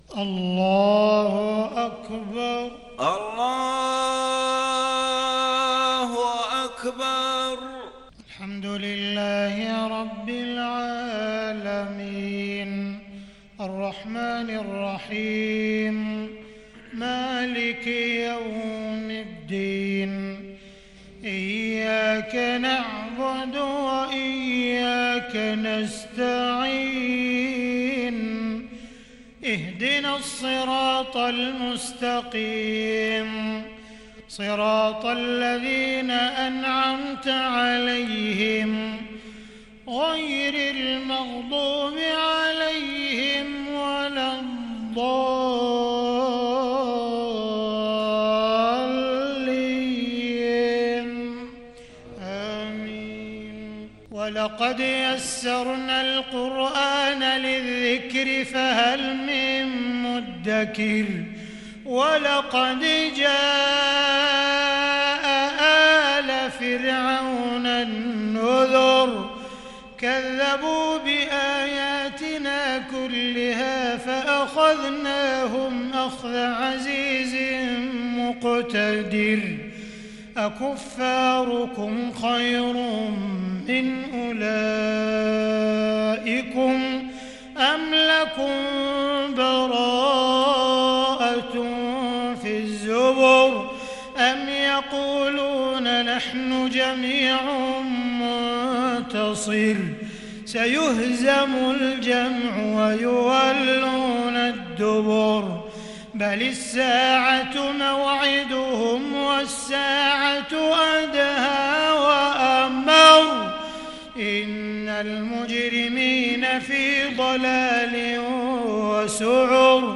صلاة العشاء للشيخ عبدالرحمن السديس 8 ربيع الآخر 1442 هـ
تِلَاوَات الْحَرَمَيْن .